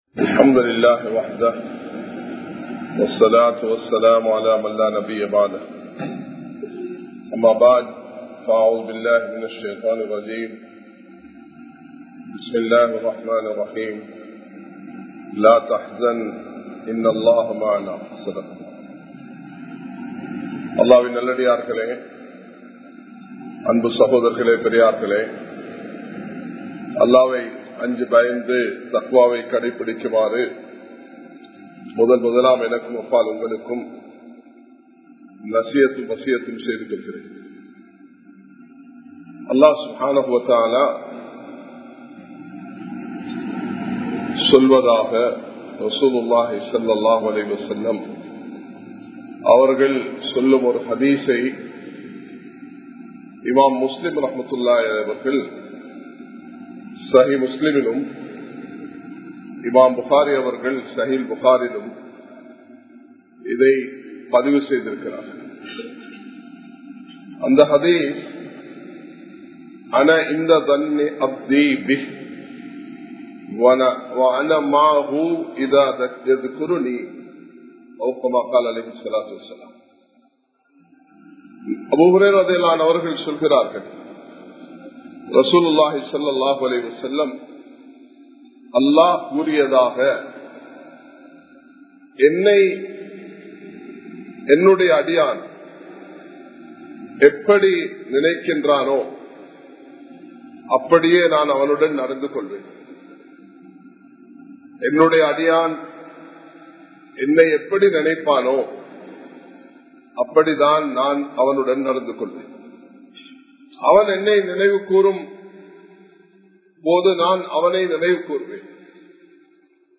Kollupitty Jumua Masjith